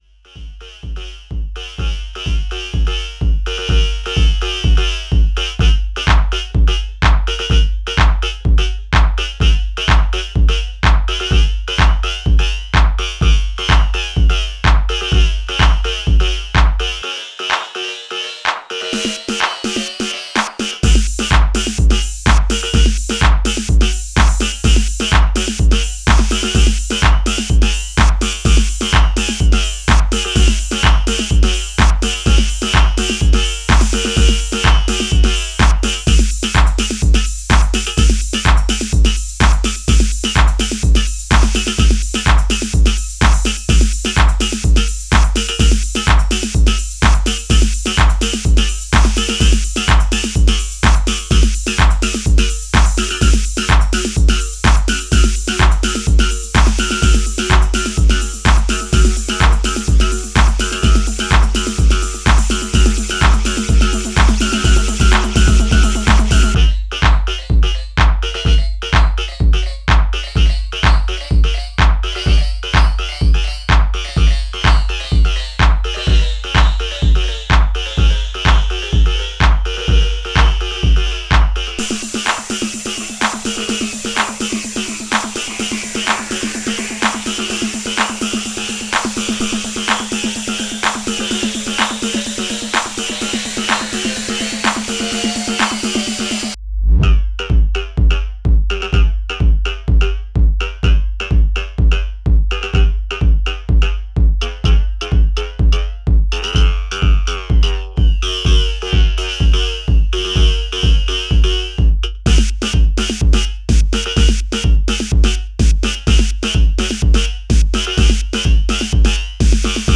Heavy jacking chi-tracks!